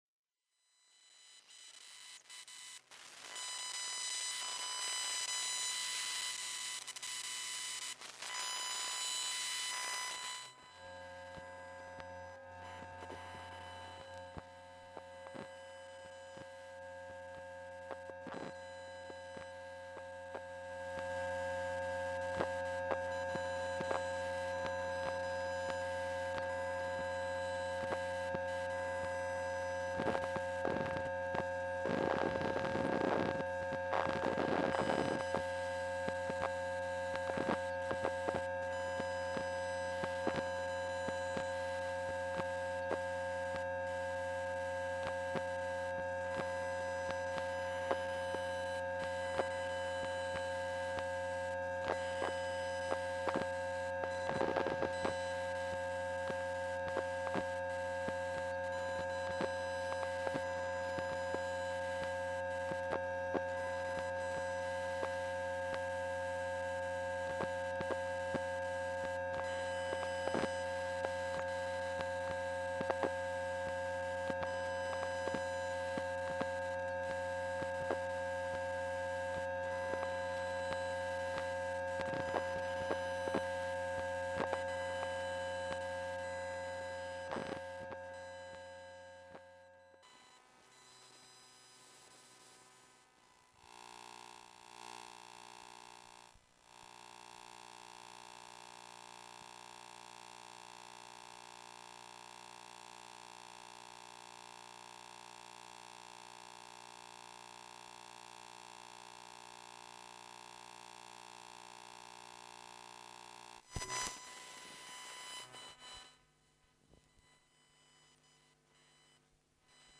Household electronics